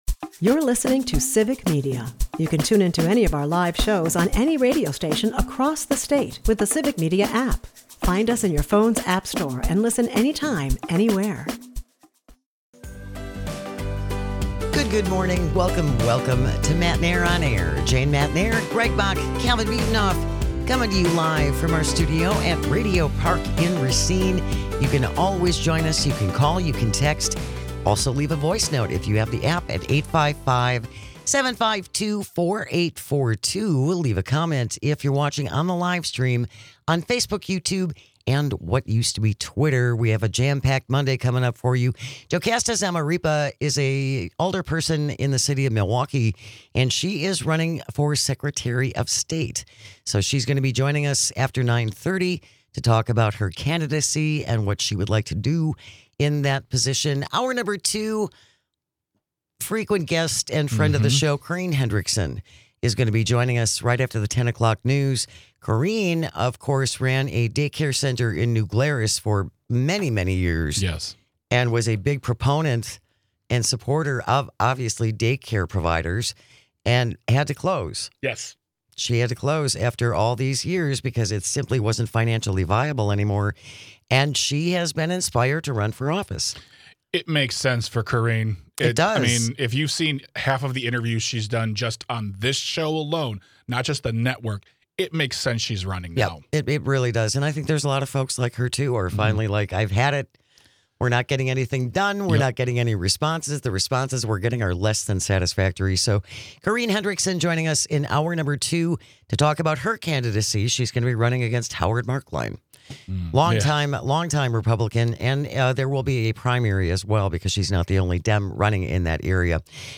We welcome her to the show to talk about her reasons for running and what we are hearing from the voters. As always, thank you for listening, texting and calling, we couldn't do this without you!